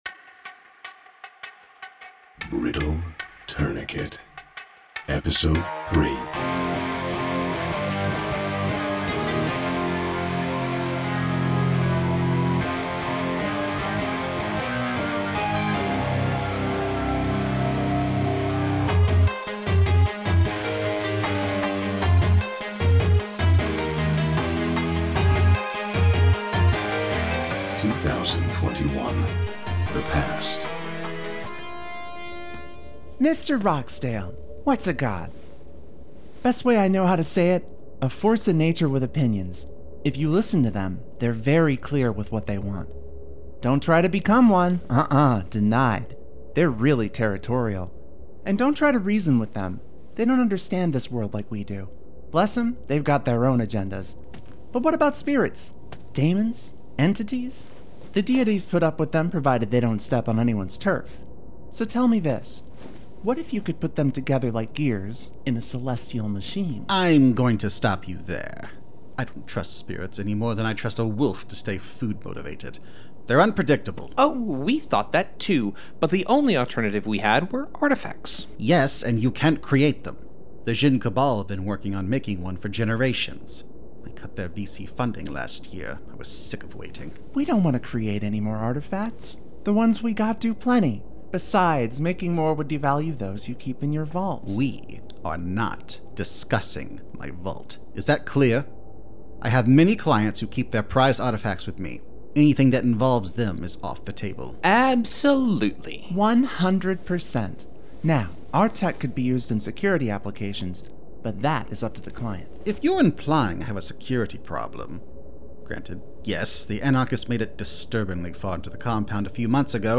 Brittle Tourniquet - Audio Drama
Mansion sitting room, flashback SFX: (Quiet large interior space ambiance.)